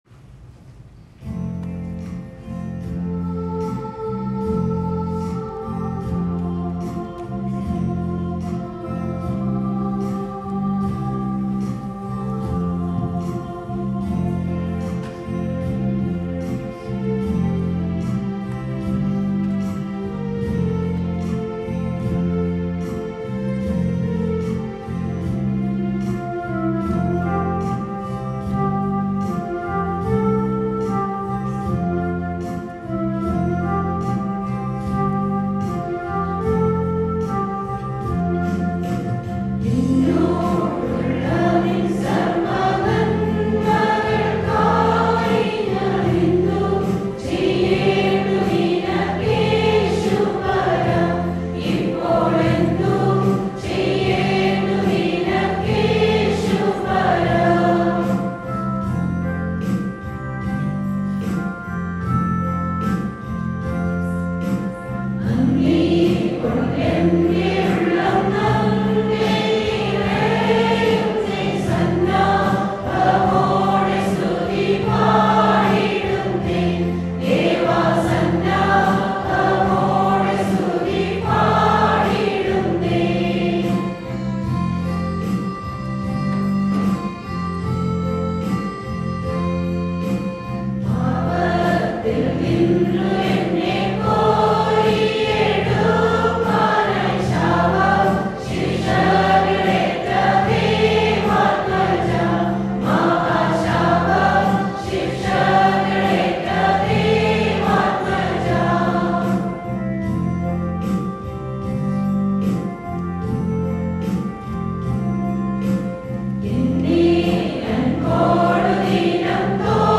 Perunnal 2023 – Church Choir